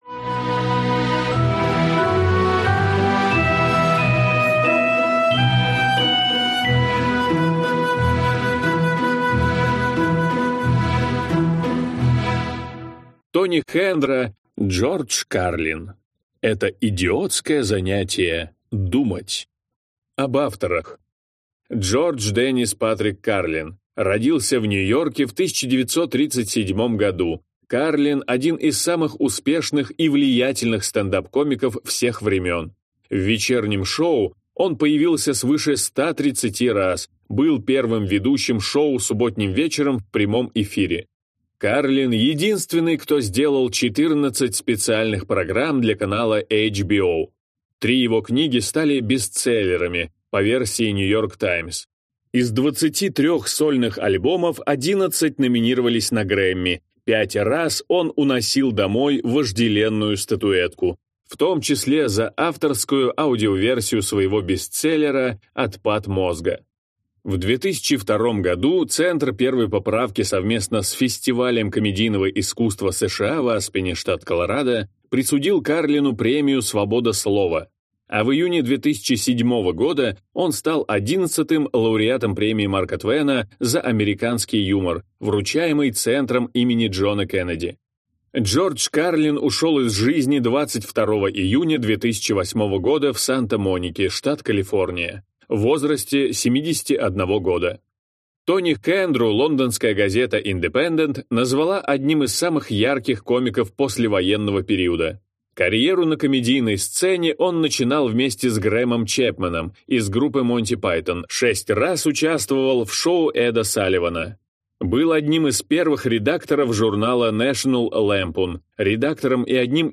Аудиокнига Это идиотское занятие – думать | Библиотека аудиокниг